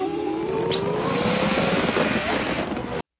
Someone begin to huffing n Puffin his/her chest/breast and belly begin to swelling inflating like a balloon based on Genie from episode ...